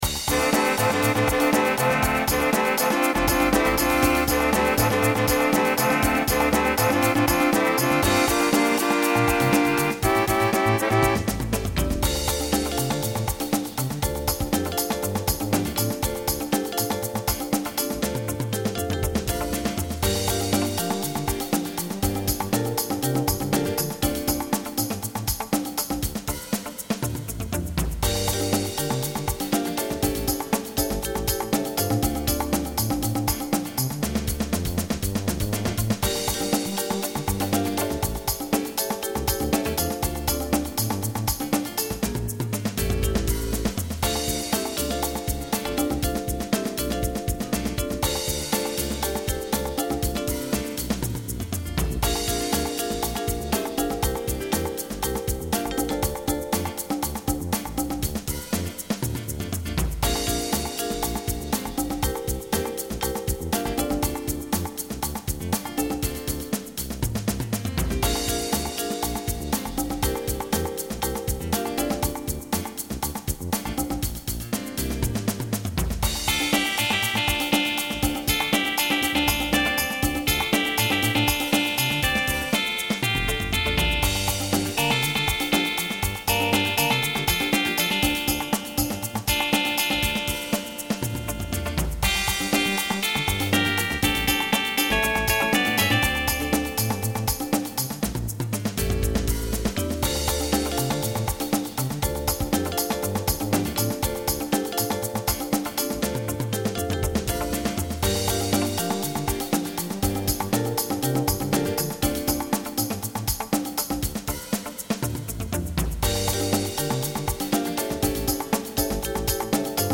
An early praise song